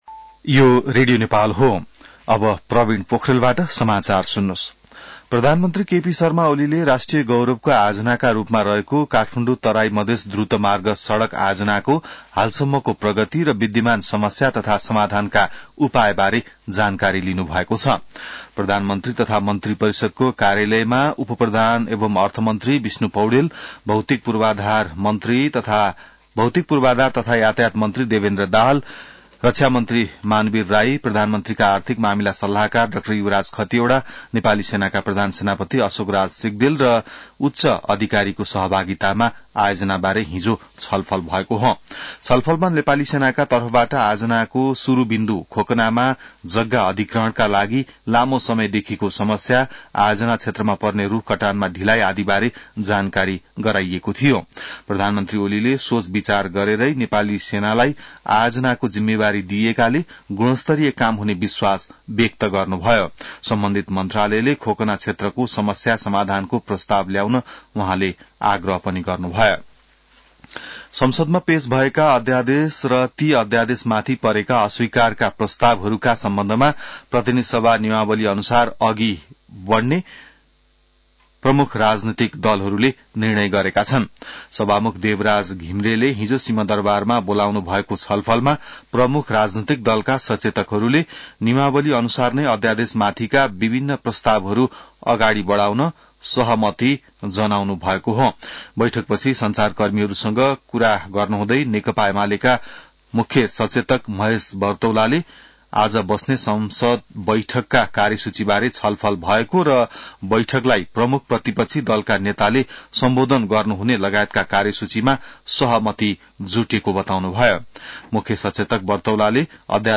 बिहान ६ बजेको नेपाली समाचार : २५ माघ , २०८१